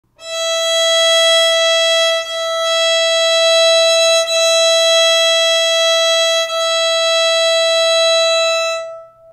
• E5, the thinnest string
Tuning-the-violin-for-Beginners-sound-of-open-E-string.mp3